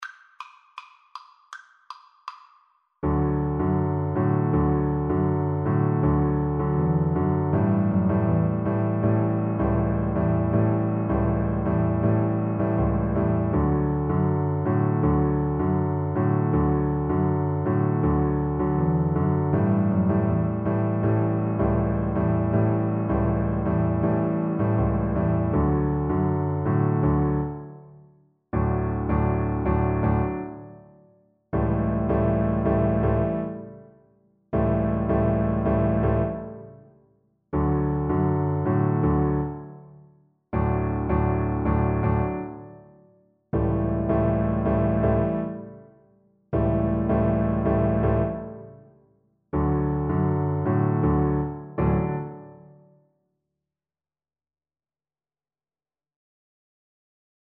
Play (or use space bar on your keyboard) Pause Music Playalong - Piano Accompaniment Playalong Band Accompaniment not yet available transpose reset tempo print settings full screen
Double Bass
D major (Sounding Pitch) (View more D major Music for Double Bass )
Presto (View more music marked Presto)
World (View more World Double Bass Music)